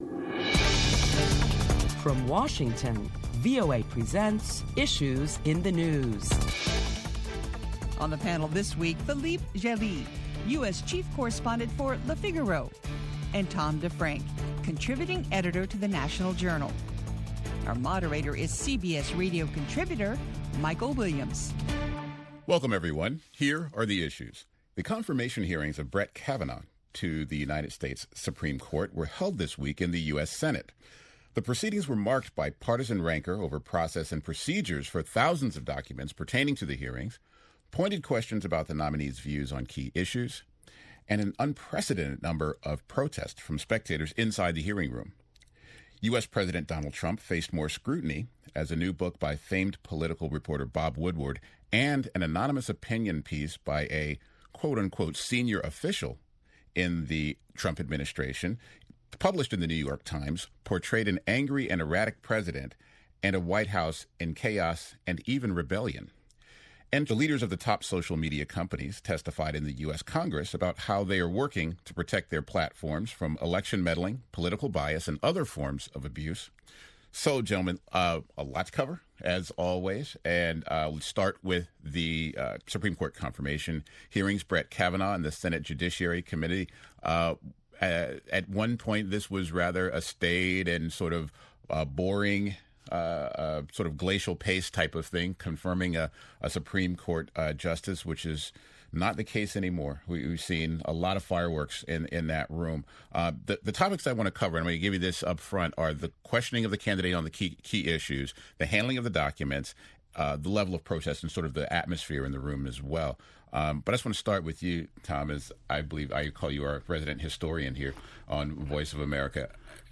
Listen in as top Washington journalists talk about the week's headlines including the contentious Senate hearings on President Trump’s Supreme Court nominee.